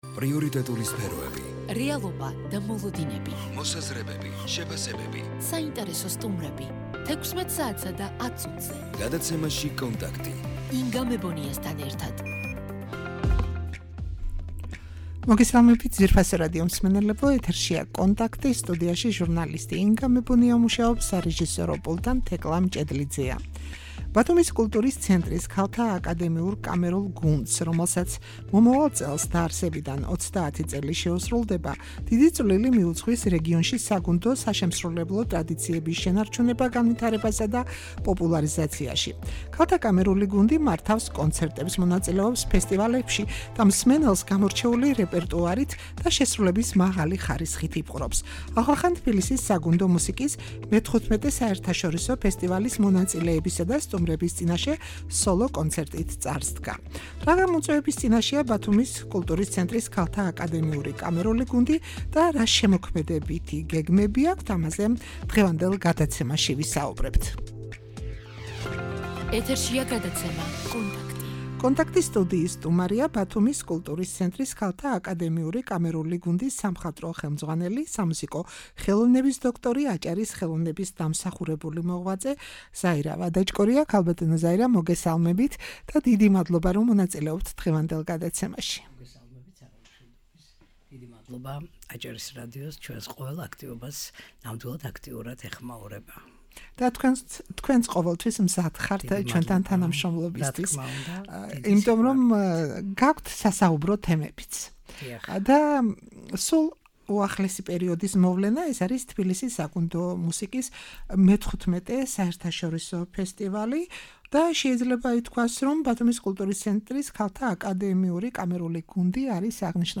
# ბათუმის კულტურის ცენტრის ქალთა აკადემიური კამერული გუნდი